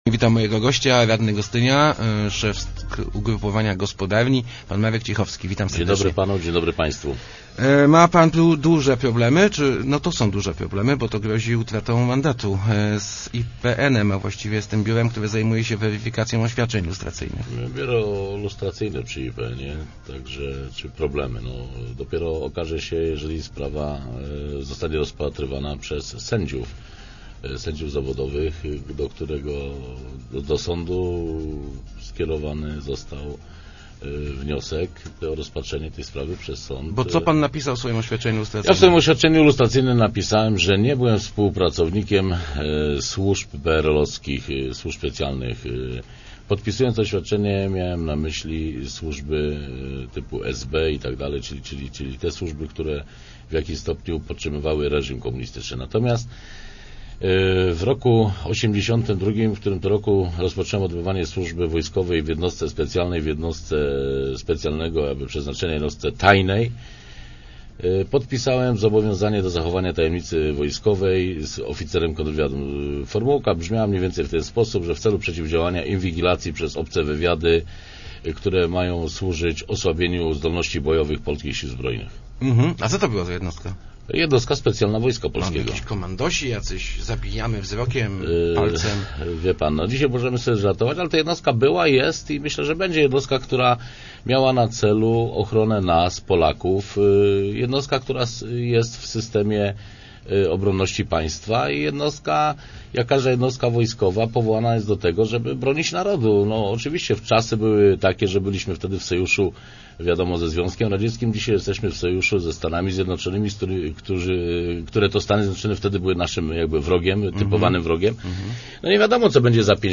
W Rozmowach Elki Marek Cichowski przyznał, że podpisał zobowiązanie do współpracy z wojskowym kontrwywiadem.